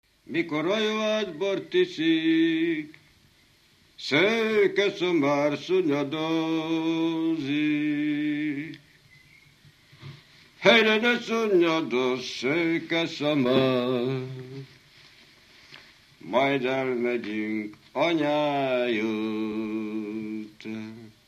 Alföld - Jász-Nagykun-Szolnok vm. - Csataszög (Nagykörű)
Stílus: 4. Sirató stílusú dallamok
Kadencia: 5 (4) 1 1